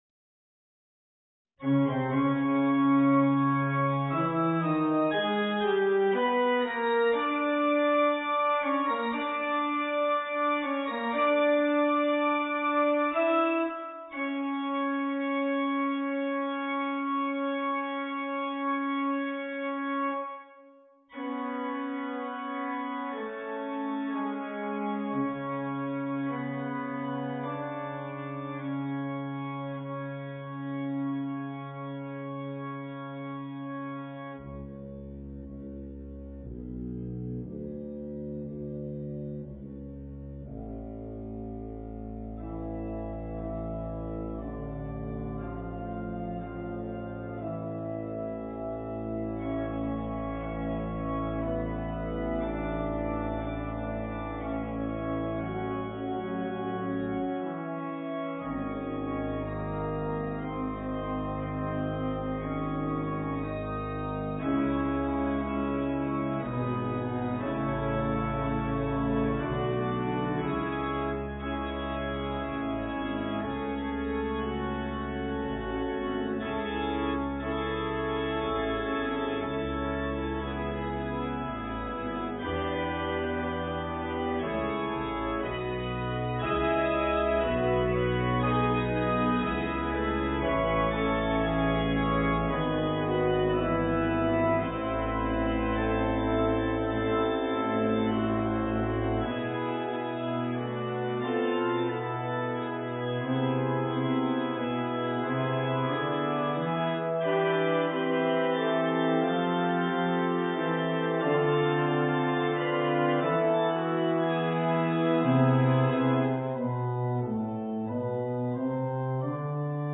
for organ